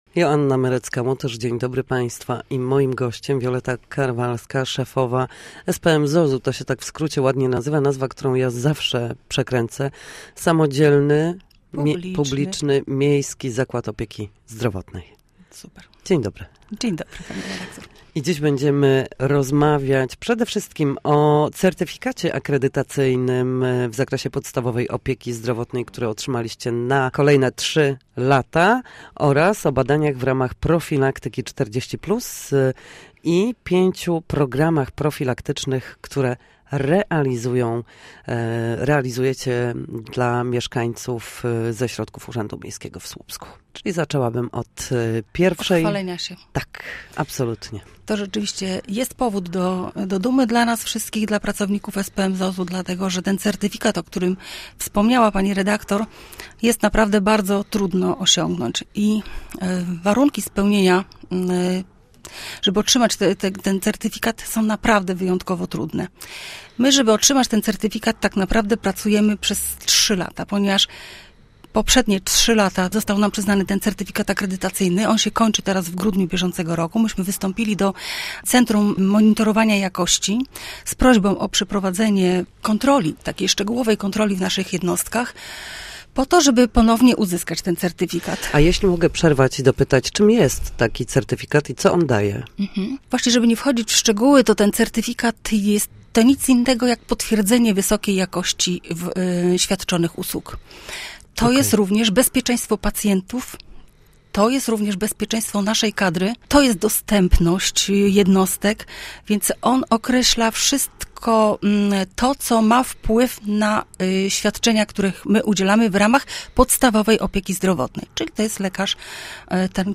Na naszej antenie mówiła o otrzymanym przez SPMZOZ certyfikacie akredytacyjnym w zakresie podstawowej opieki zdrowotnej na kolejne trzy lata oraz badaniach w ramach profilaktyki 40+ i o pięciu programach profilaktycznych, które realizują dla mieszkańców miasta ze środków Urzędu Miejskiego.